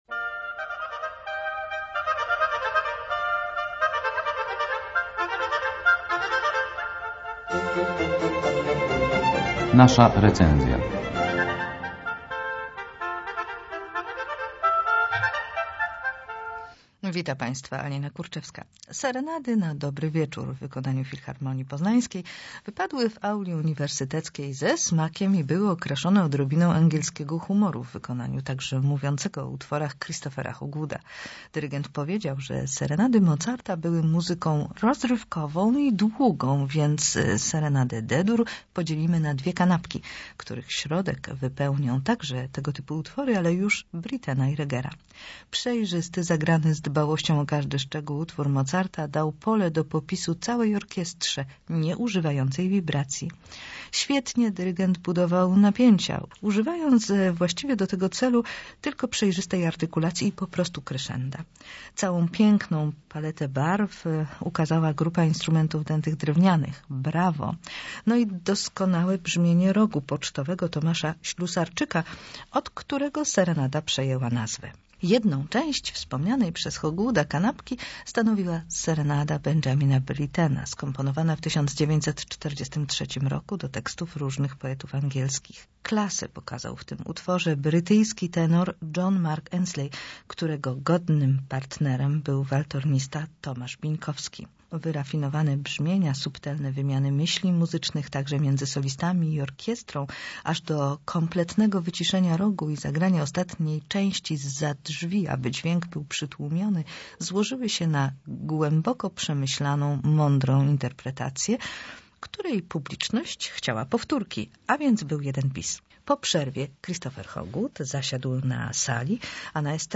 byz02j0dm7bor7d__recenzja_z_hogwooda_w_filharmonii.mp3